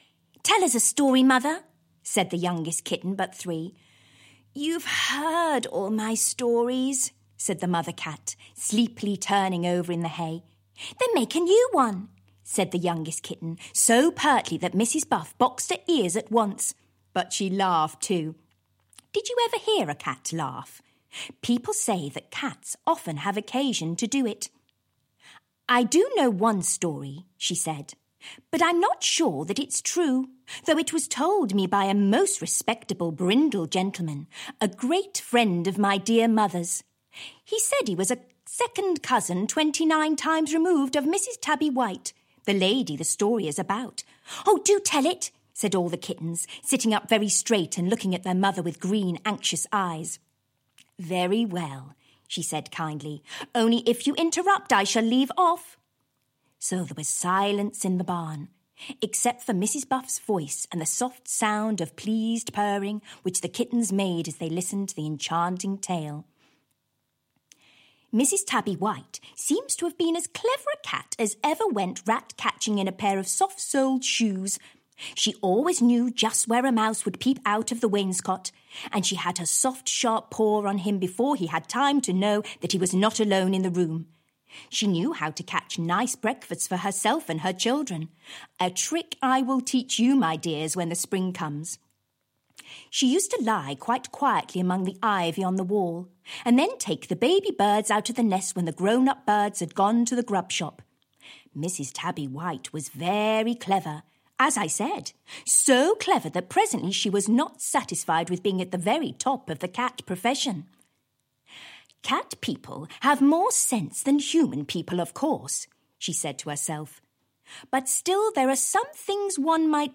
Audio knihaShort Stories About Kittens (EN)
Ukázka z knihy